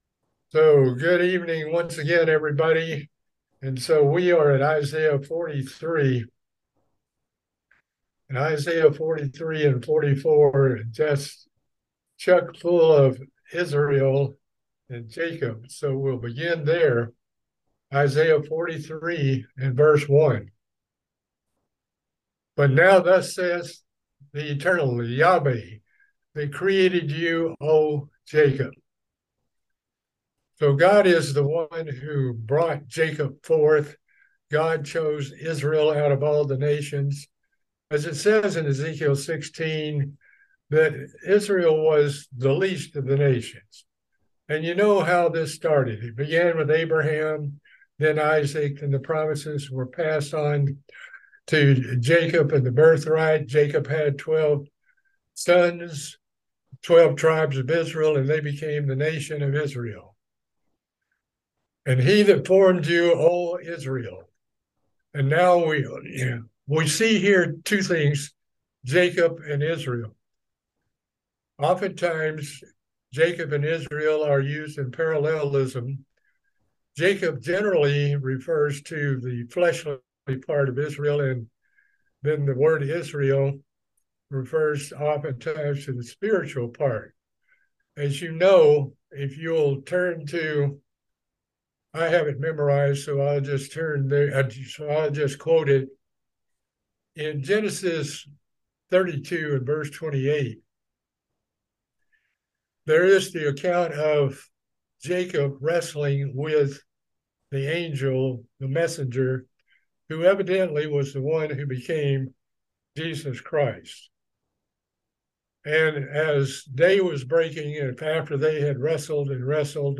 This is a continuation of a study into the book of Isaiah. We continue with the chapter of 43.